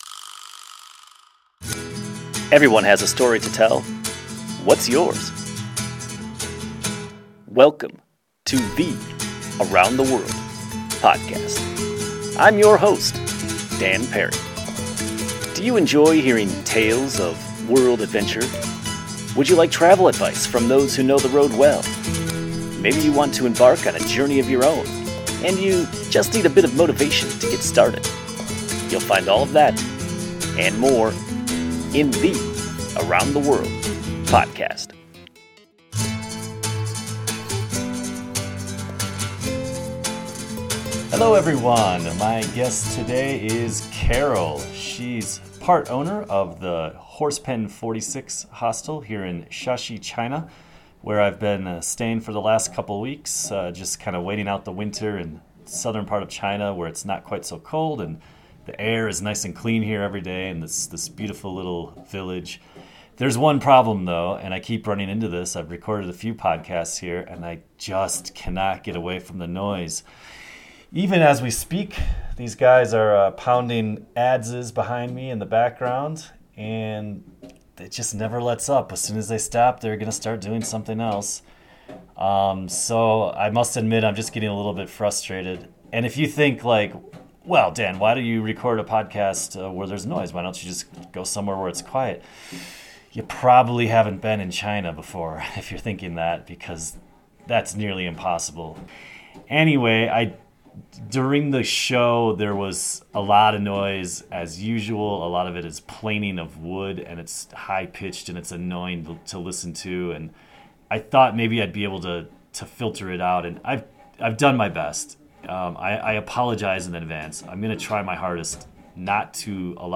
We had a fun and lighthearted conversation in the hostel's coffee shop.